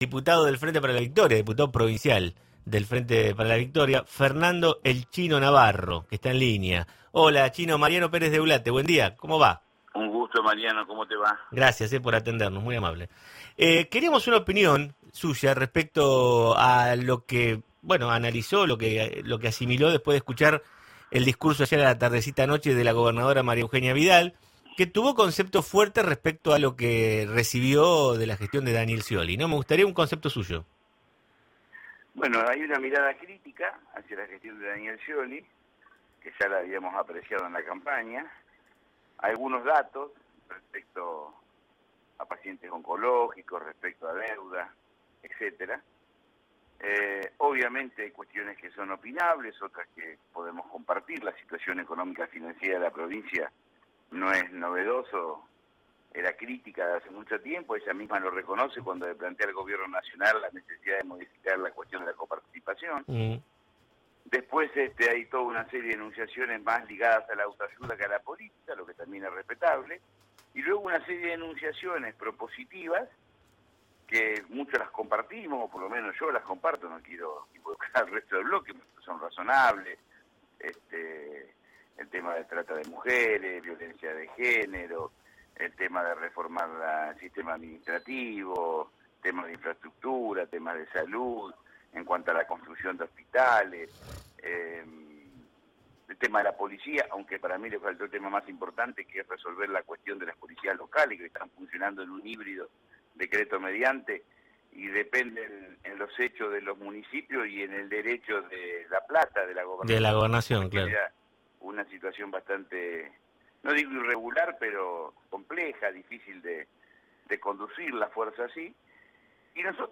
En la entrevista que brindó a LA REDONDA 100.3, Navarro manifestó que comparte la idea de que la provincia está pasando por un mal momento económico pero que a Vidal “le faltó hablar” sobre un tema que a su entender tiene mucha importancia, el de la policía local.